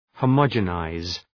Προφορά
{hə’mɒdʒə,naız} (Ρήμα) ● κάμνω ομοιογενές